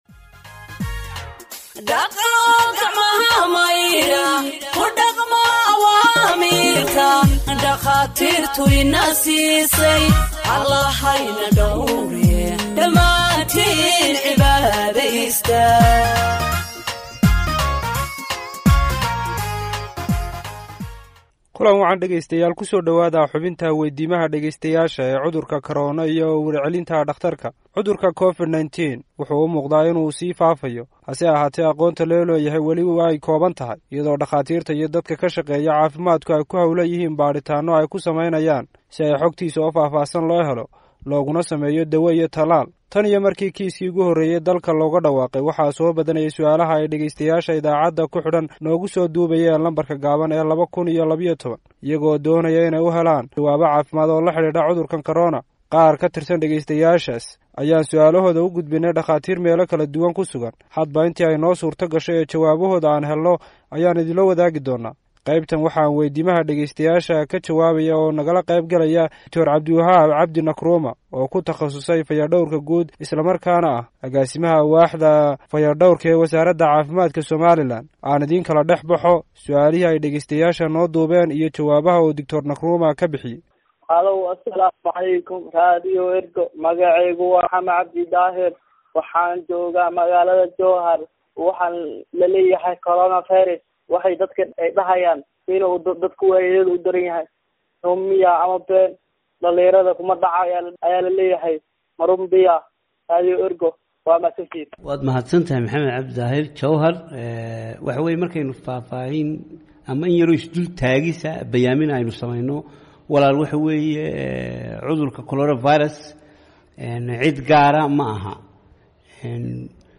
Health expert answers listeners’ questions on COVID 19 (8)
Radio Ergo provides Somali humanitarian news gathered from its correspondents across the country for radio broadcast and website publication.